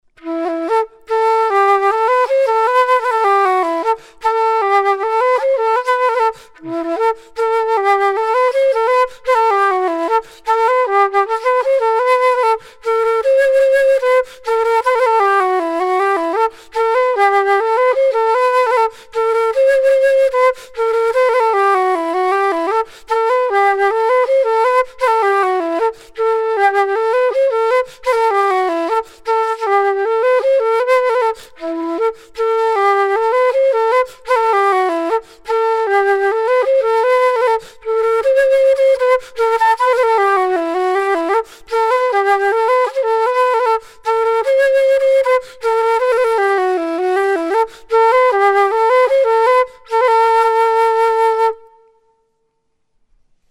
Elle n'a pas la même vibration que les flûtes en bois : le son est clair, puissant, mais un peu moins chaleureux.